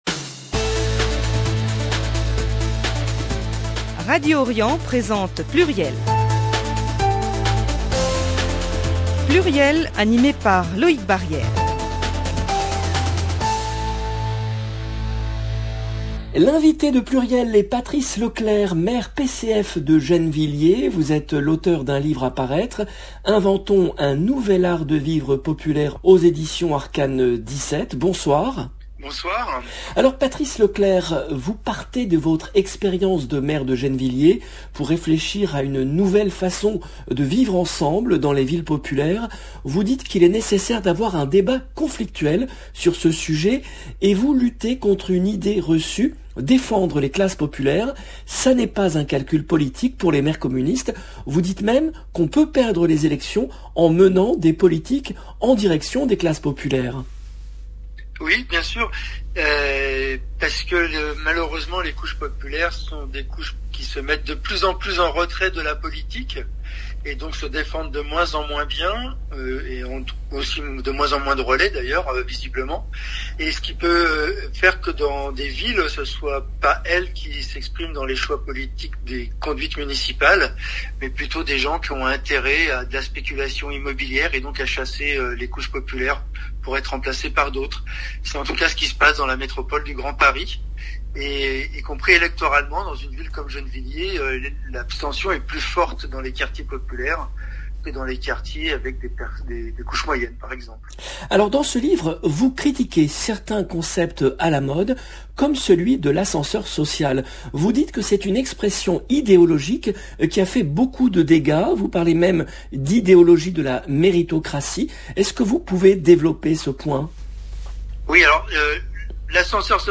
le rendez-vous politique du mercredi 19 janvier 2022 L’invité de PLURIEL est Patrice Leclerc , maire PCF de Gennevilliers.